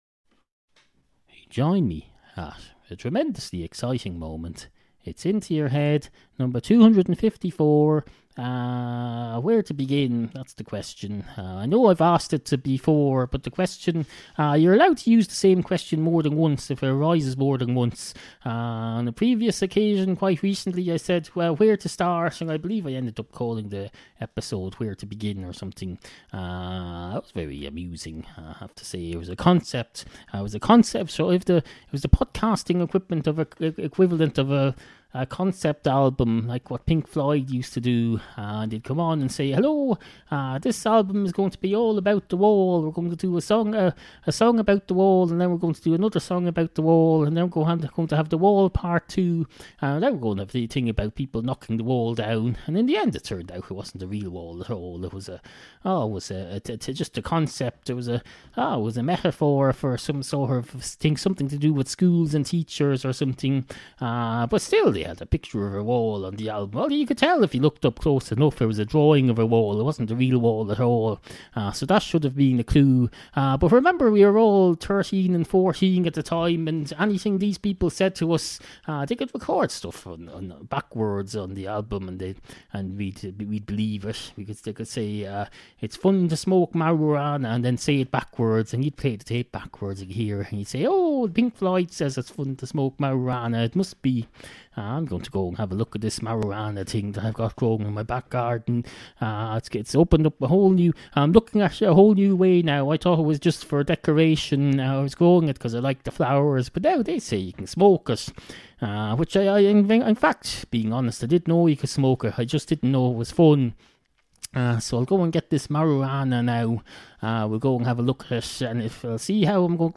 Obscure 21st Century Irish audio comedy series